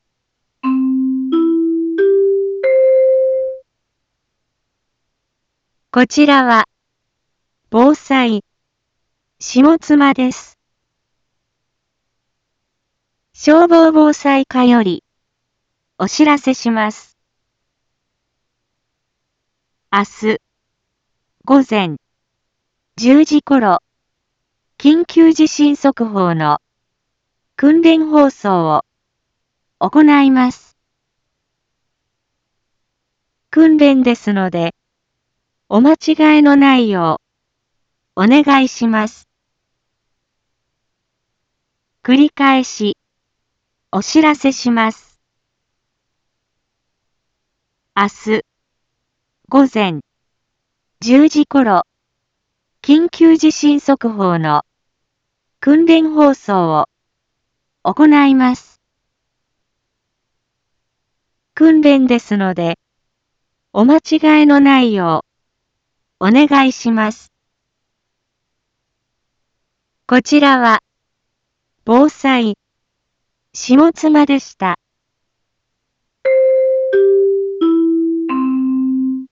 一般放送情報
Back Home 一般放送情報 音声放送 再生 一般放送情報 登録日時：2023-06-14 18:31:23 タイトル：緊急地震速報訓練（前日） インフォメーション：こちらは、防災、下妻です。